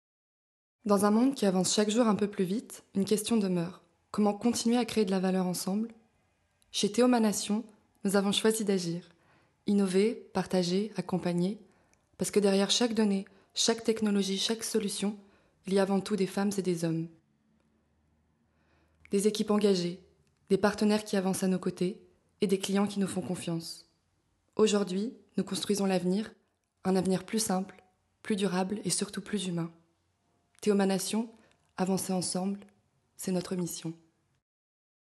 PUB VOIX V2
Voix off